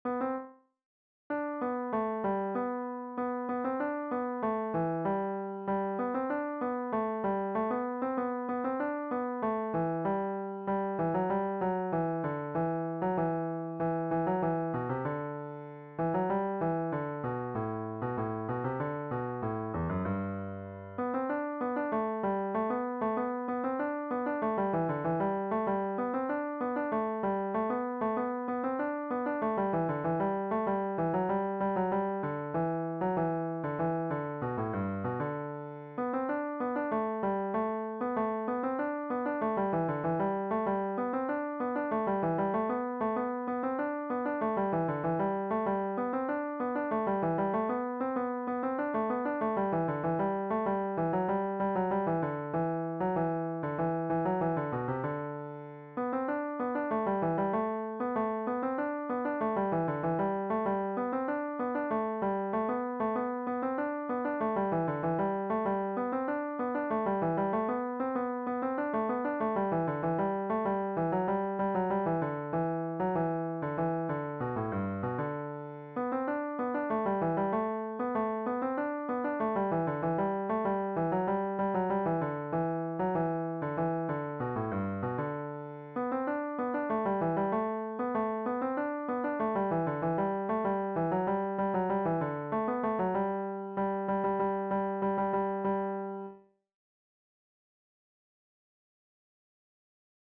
DIGITAL SHEET MUSIC - CELLO SOLO
Cello Melody Only, Traditional Fiddle Tune